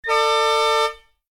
KART_raceStart2.ogg